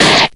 Slash8.ogg